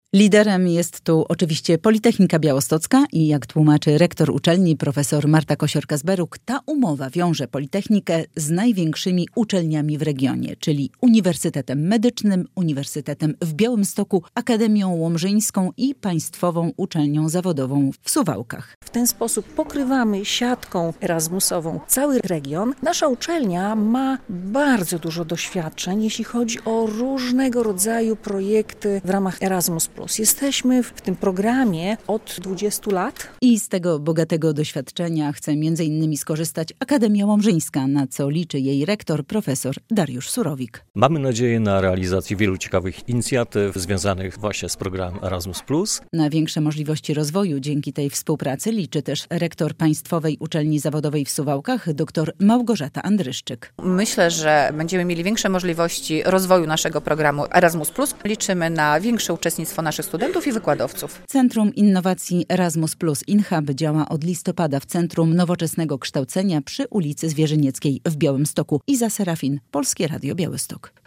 Umowa w sprawie programu Erasmus - relacja